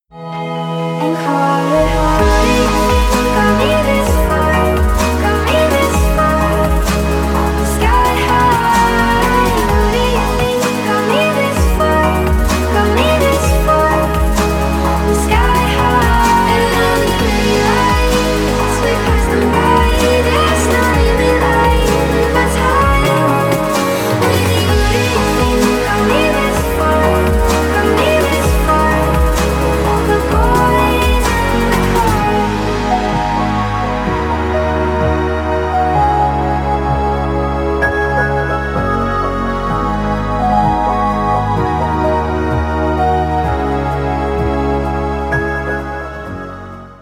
• Качество: 320, Stereo
dance
vocal